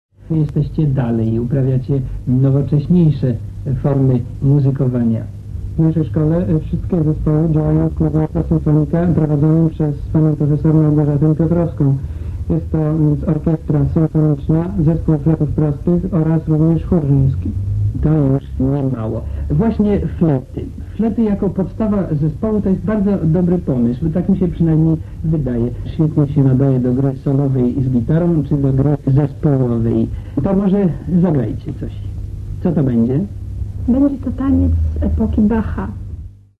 Tak graliśmy i śpiewaliśmy w pierwszej połowie lat siedemdziesiątych.
Tylko tyle udało się jak na razie odnaleźć, a ich jakość, delikatnie mówiąc, nie jest najlepsza.
Audycja radiowa z 1973 roku - część 1